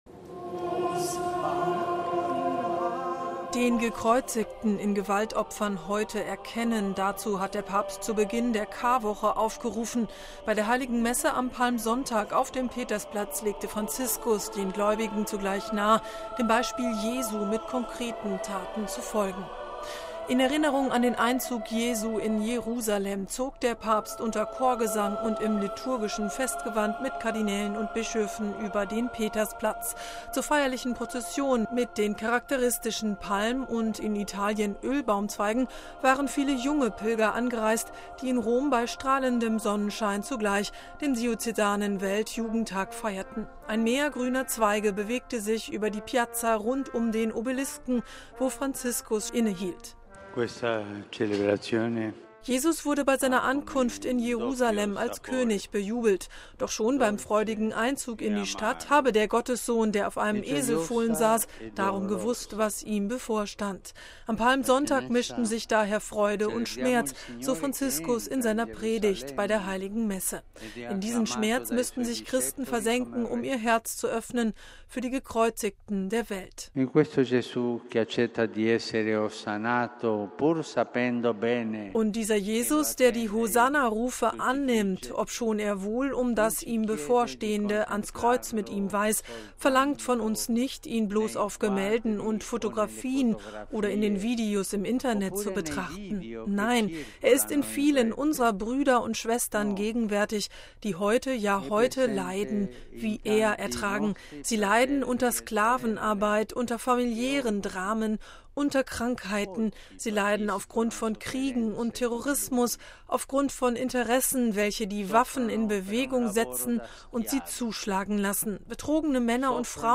Bei der Heiligen Messe an Palmsonntag auf dem Petersplatz legte Franziskus den Gläubigen zugleich nahe, dem Beispiel Jesu mit konkreten Taten zu folgen.
In Erinnerung an den Einzug Jesu in Jerusalem zog der Papst unter Chorgesang und im liturgischen Festgewand mit Kardinälen und Bischöfen über dem Petersplatz.